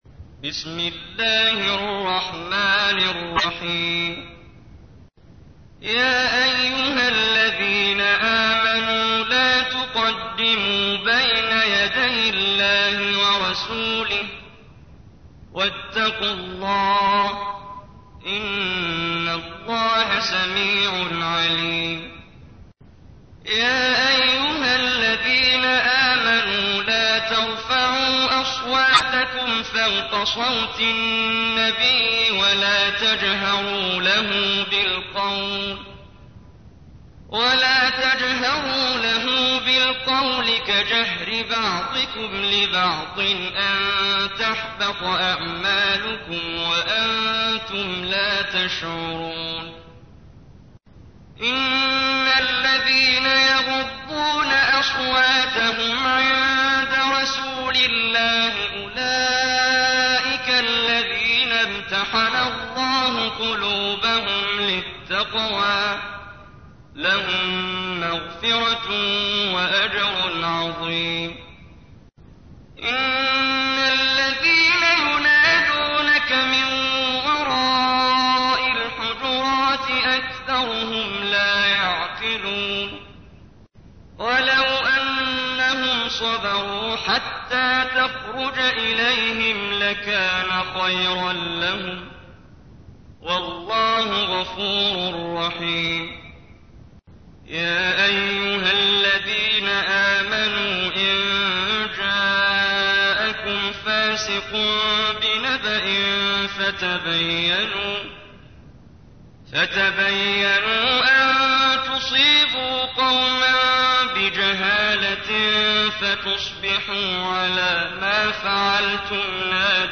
تحميل : 49. سورة الحجرات / القارئ محمد جبريل / القرآن الكريم / موقع يا حسين